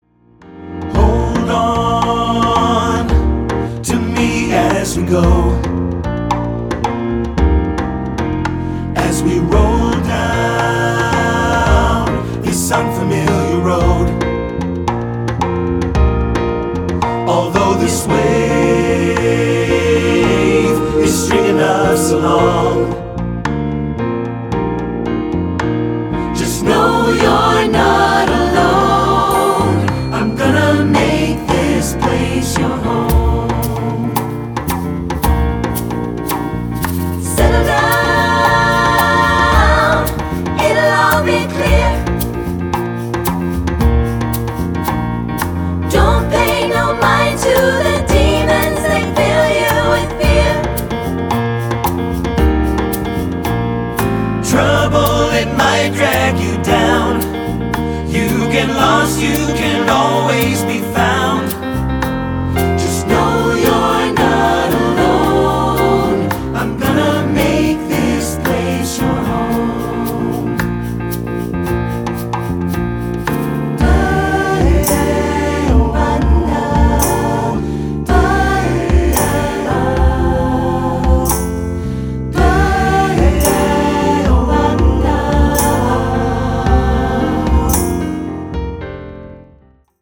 SATB
SATB Divisi
Choral Early 2000's Pop